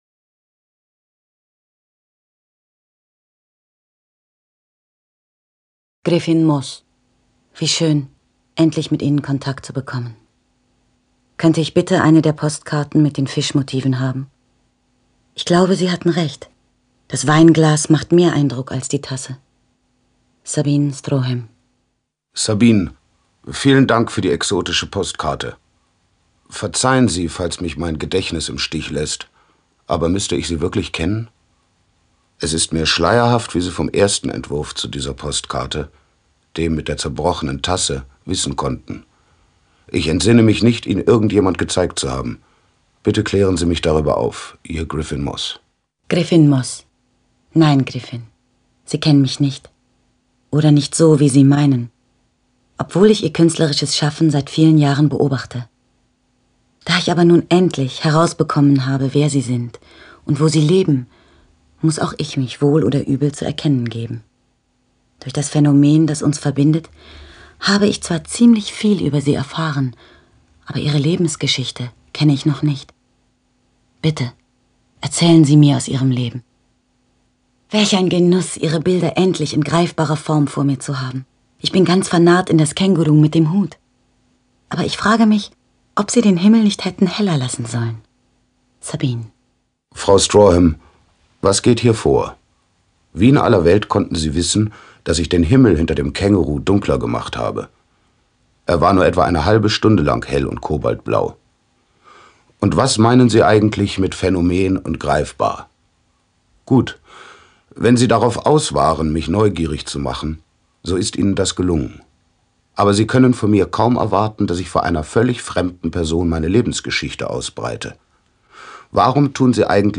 Mit ganz großen Stimmen & viel Gefühl von Katja Riemann, Ben Becker und Otto Sander.